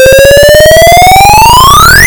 Game over
This sound effect is produced by the routine at 35140 during the game over sequence.
game-over.ogg